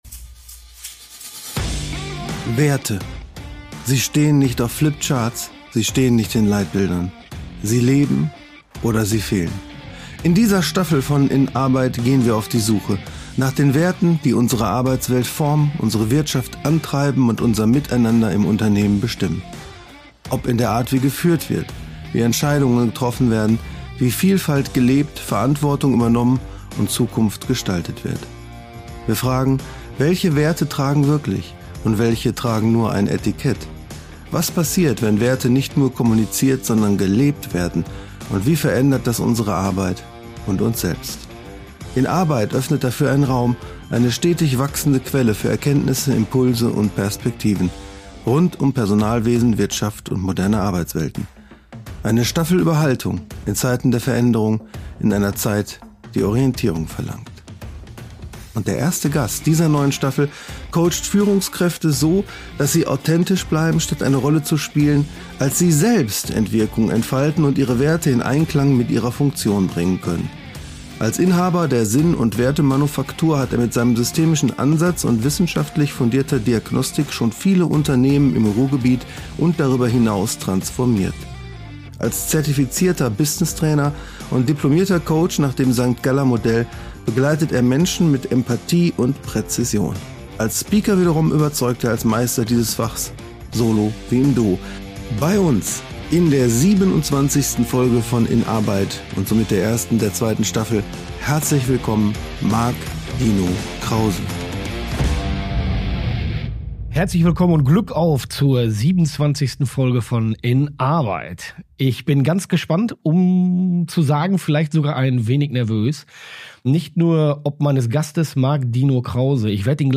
Ein Gespräch über die vier großen V der modernen Menschenführung: Vertrauen, Verantwortung, Veränderung und das Vorleben der Prinzipien.